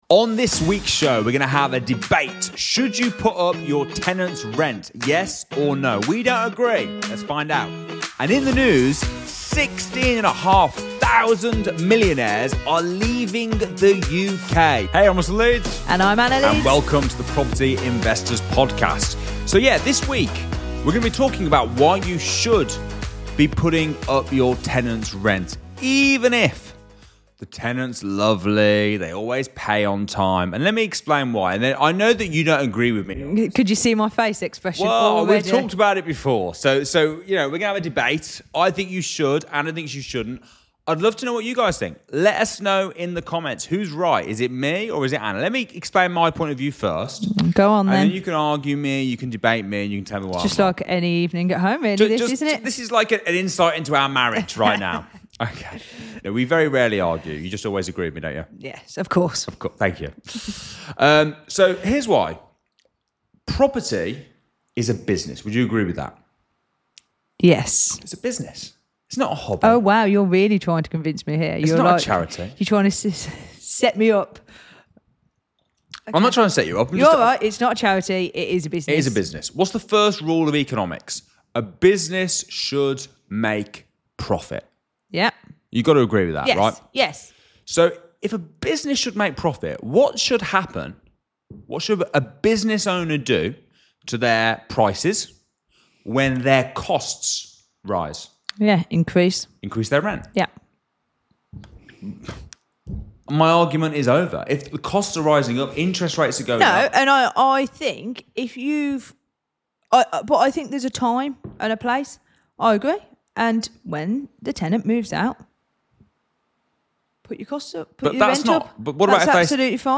HEATED DEBATE